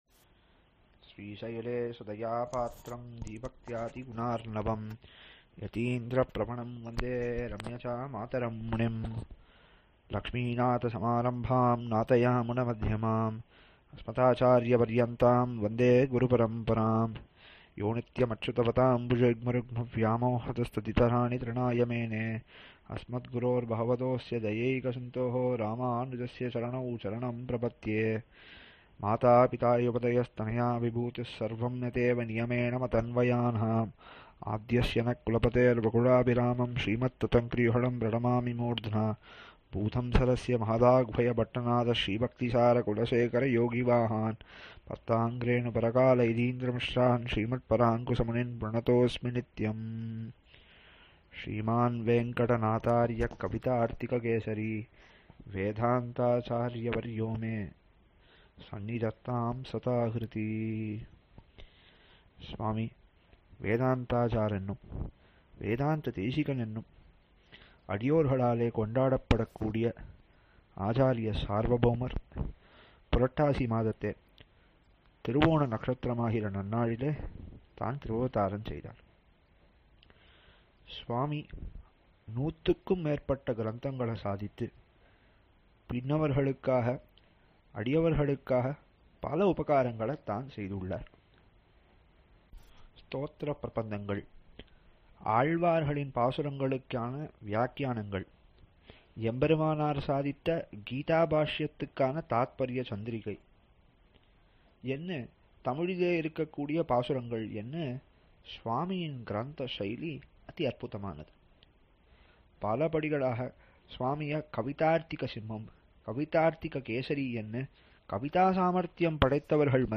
Dramidopanishat Thathparya Rathnavali Series – 2nd Ten – 2023-Sobakruth-Purattasi Upanyasangal